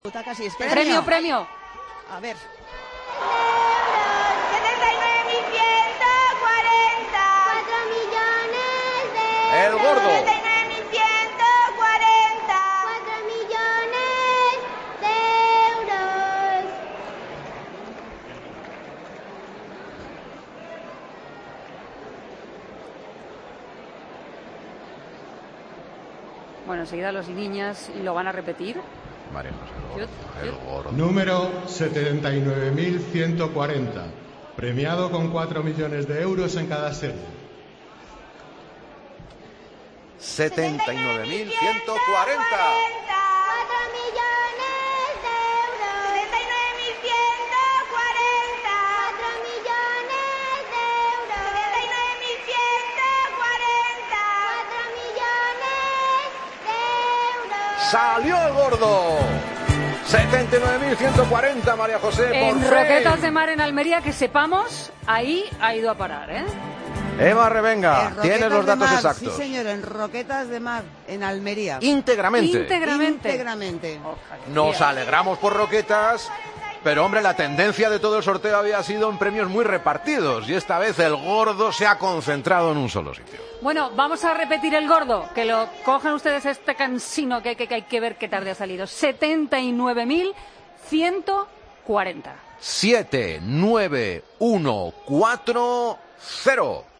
Los niños de San Ildefonso cantan El Gordo de Navidad: 79.140